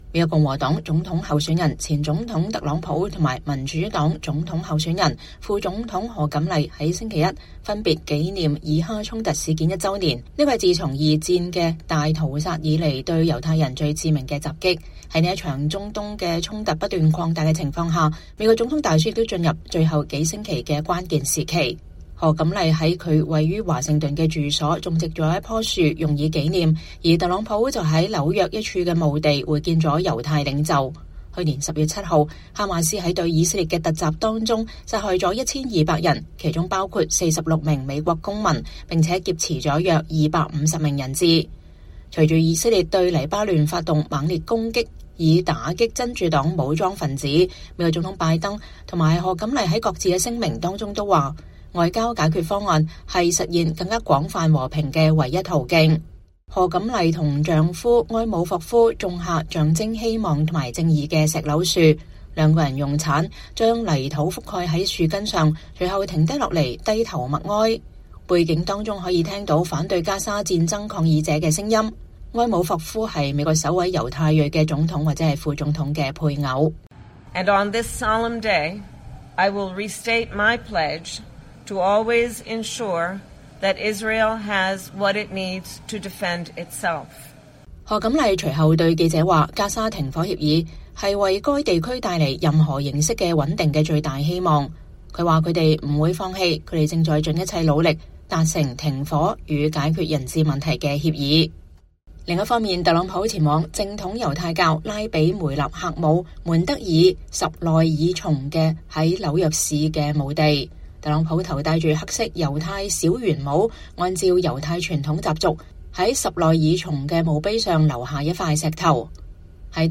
背景中可以聽到反對加沙戰爭抗議者的聲音。